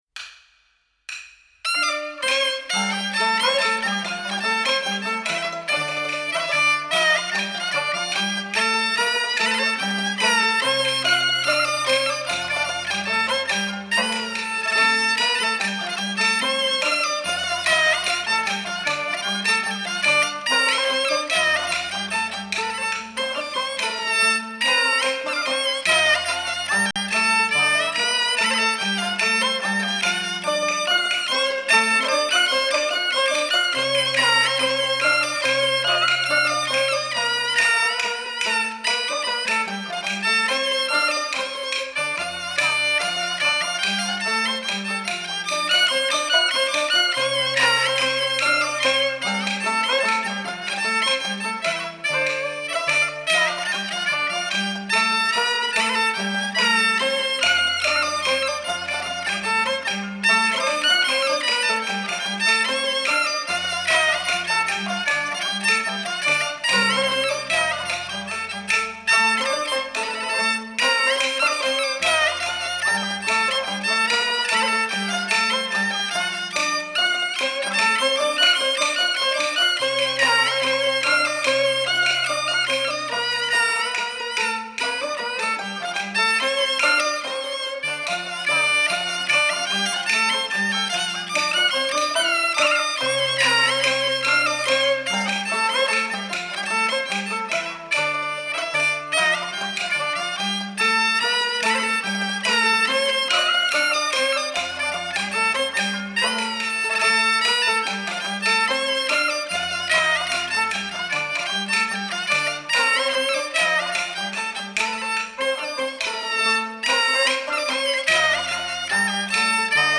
0017-京胡名曲海青歌.mp3